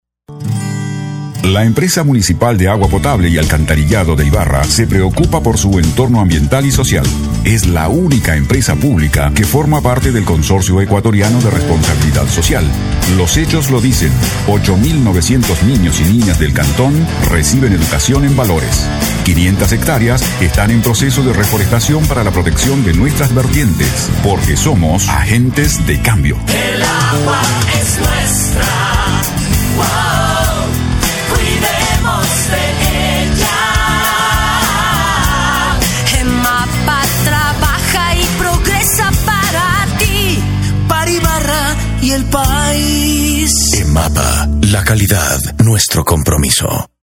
Sprecher spanisch (Südamerika). Dicción clara, firme, segura.
Sprechprobe: Werbung (Muttersprache):